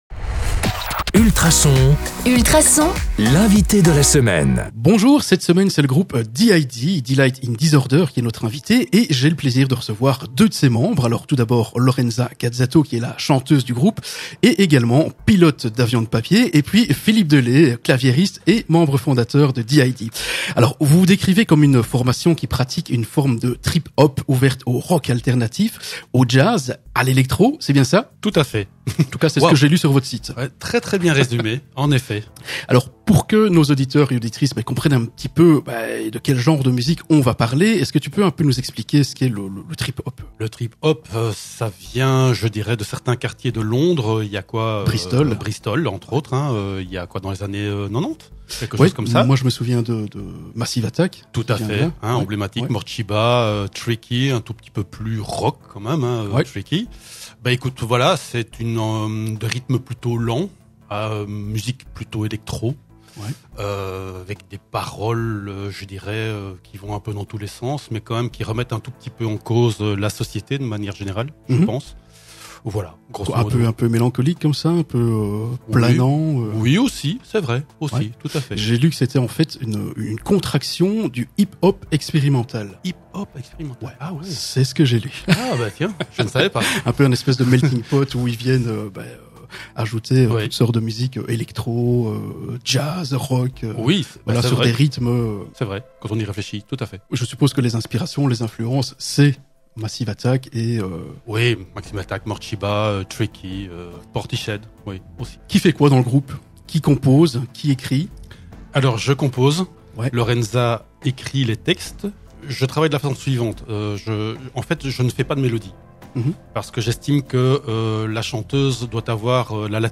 D.i.D / Delight In Disorder est un groupe belge qui pratique une forme de trip-hop ouverte au rock et à l'éléctro avec une esthétique tantôt introspective, mystérieuse et douce, tantôt efficace et dynamique sur des inspirations telles que Massive Attack, Archive, David Bowie, Portishead ou encore Kate Bush.